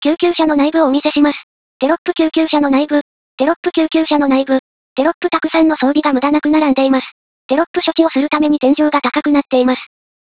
音声解説（ダウンロード）